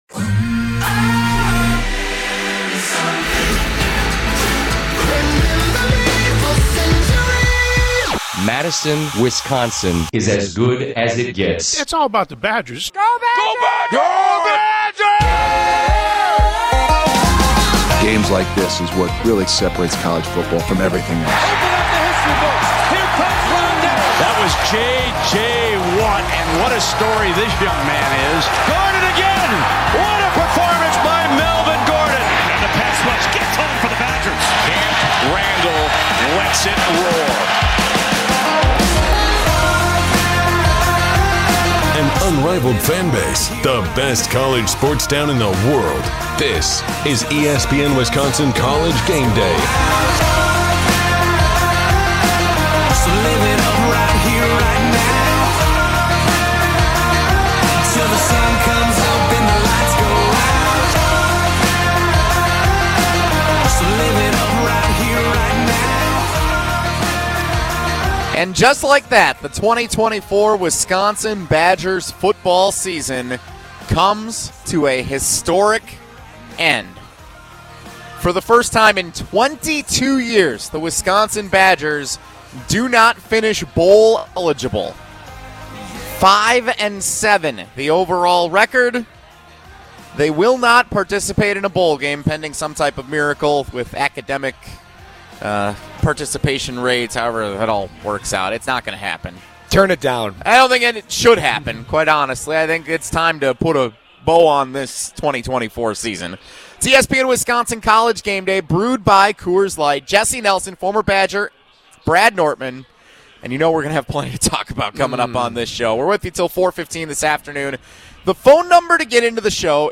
Wisconsin College GameDay - 11-29 ESPN Wisconsin College GameDay Postgame Hour 1